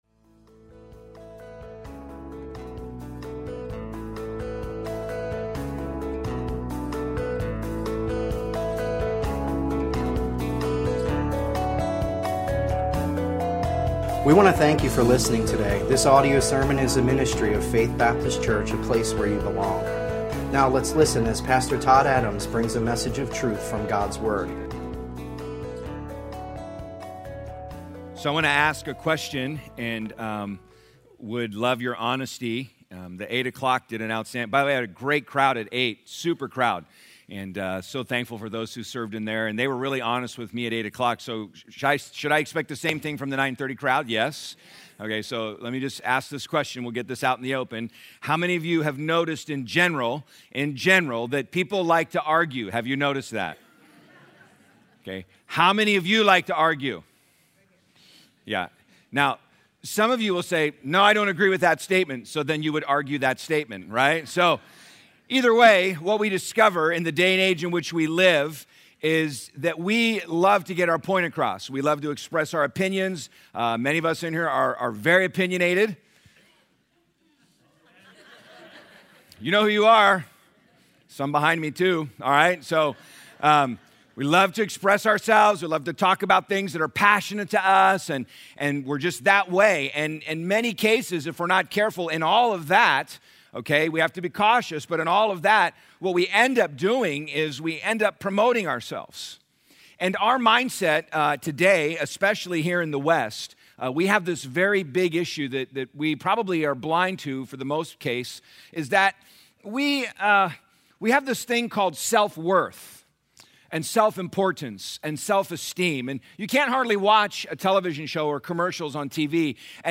Easter Sunday - Experiencing the Power of the Resurrection